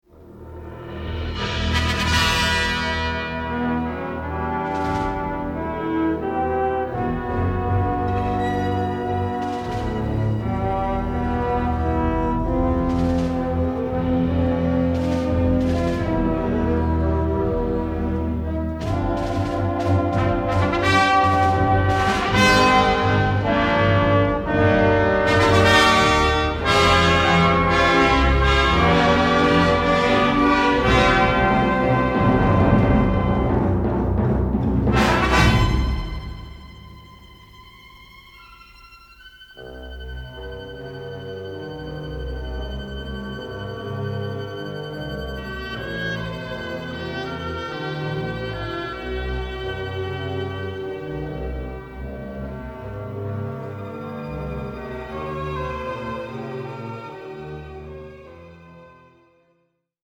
robust orchestral score